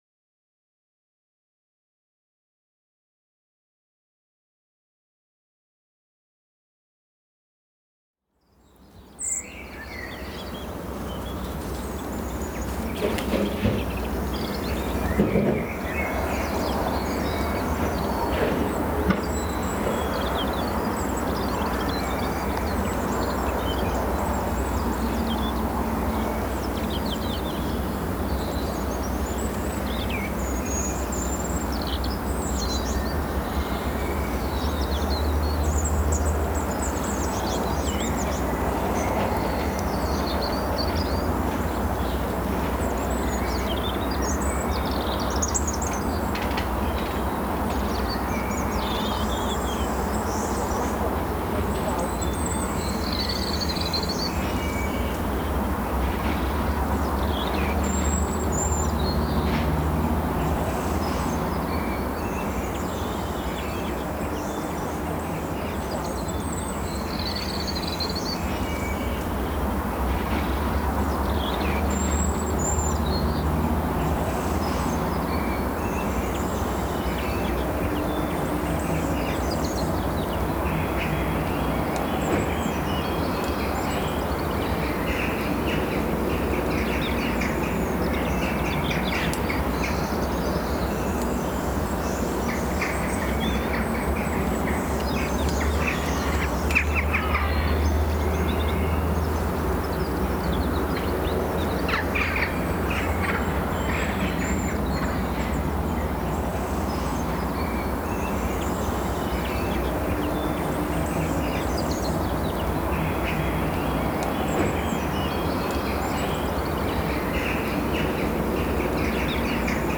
AMB_Intro_Ambience_RS.ogg